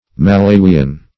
Meaning of malawian. malawian synonyms, pronunciation, spelling and more from Free Dictionary.